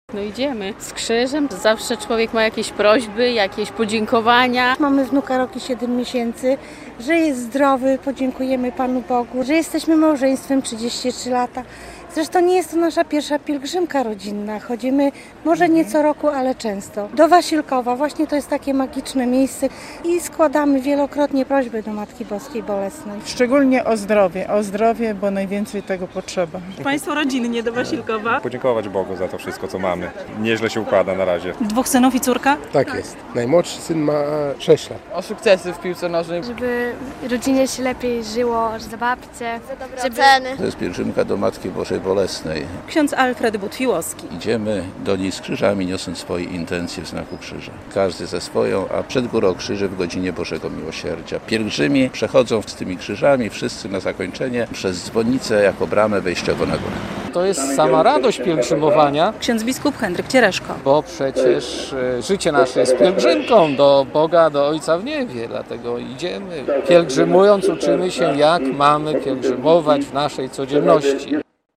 Pielgrzymka do Świętej Wody - relacja